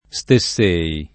St$SSo] — pass. rem. stessei [